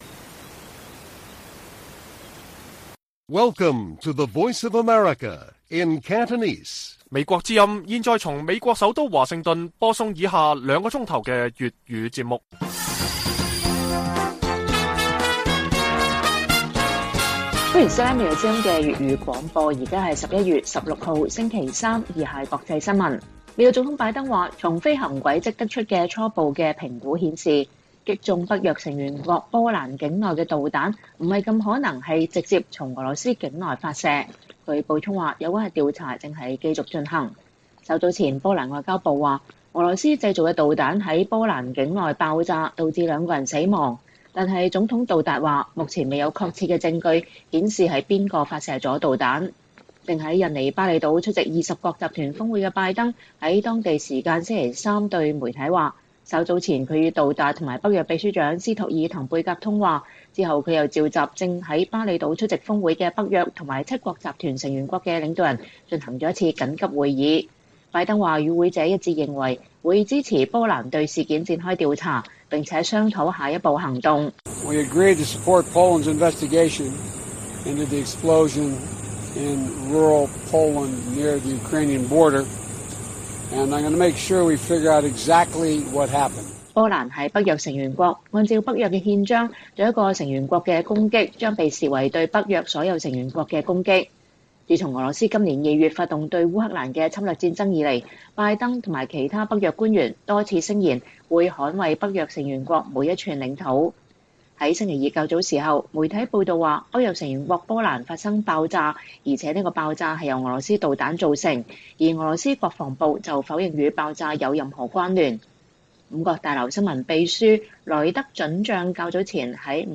粵語新聞 晚上9-10點: 拜登說波蘭境內發生的導彈爆炸不大可能從俄羅斯境內發射